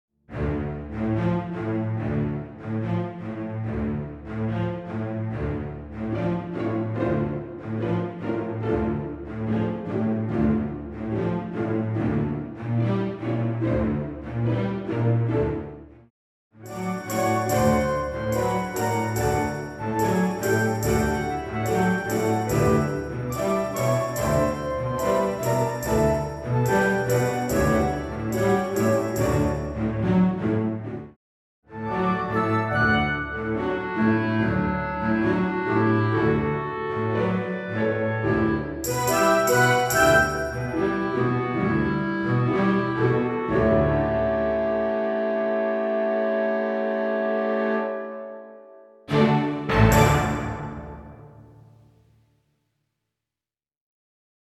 full orchestral accompaniment